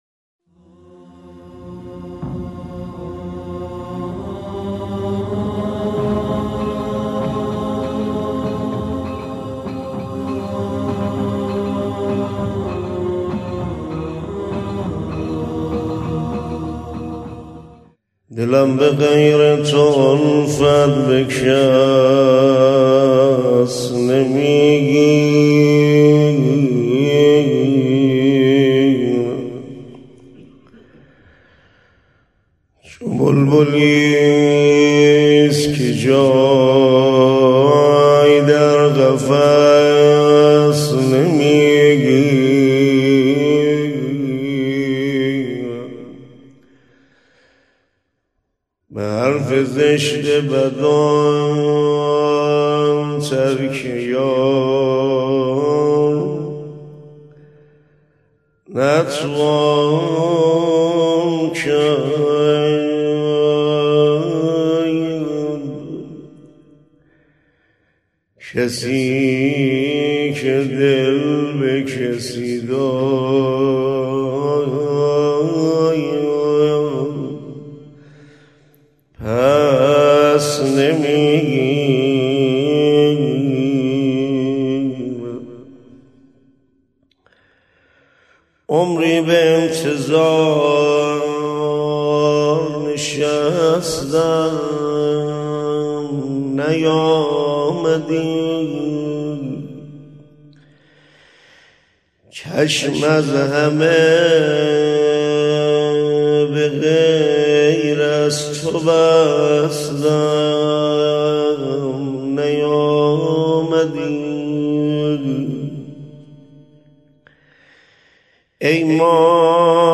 مراسم ایام عاشورا ۱۴۳۶ هجری قمری روز سوم